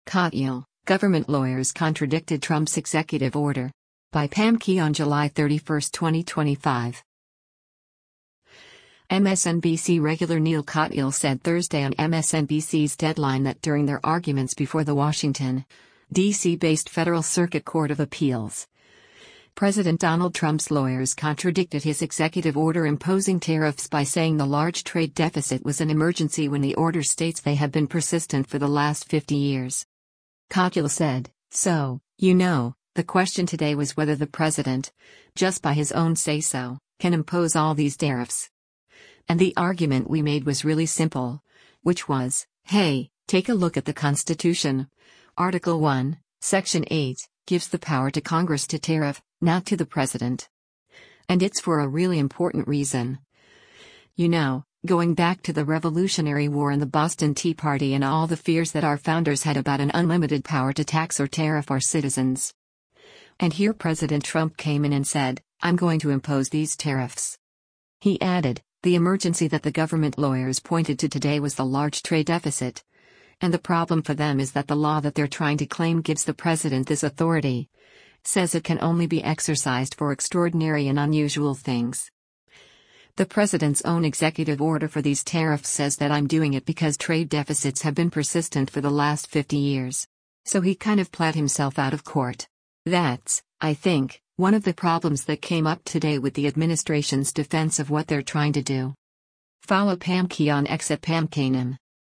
MSNBC regular Neal Katyal said Thursday on MSNBC’s “Deadline” that during their arguments before the Washington, D.C.-based Federal Circuit Court of Appeals, President Donald Trump’s lawyers contradicted his executive order imposing tariffs by saying the “large trade deficit” was an emergency when the order states they have been persistent for the last 50 years.